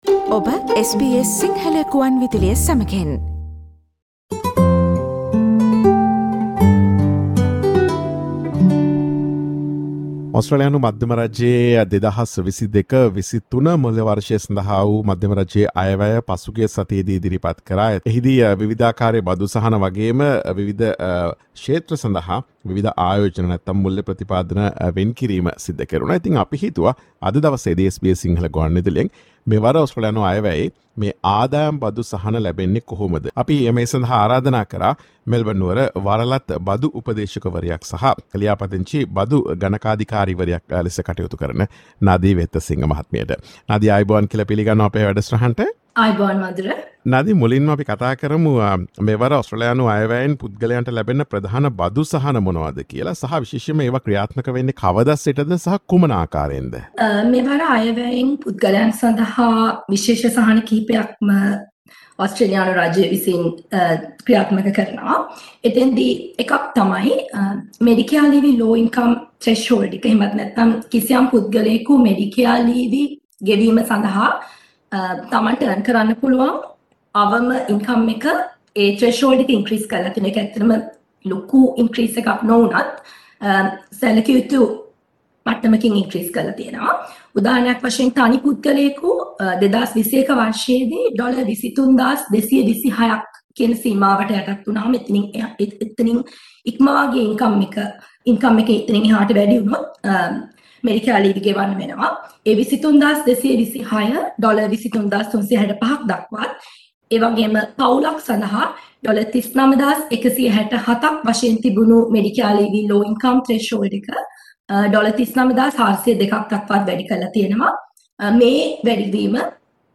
මෙවර ඔස්ට්‍රේලියානු අයවැයෙන් ලබාදුන් බදු සහන ඔබට ලැබෙන අයුරු පිළිබඳ SBS සිංහල ගුවන් විදුලිය සිදුකළ සාකච්ඡාවට සවන් දෙන්න.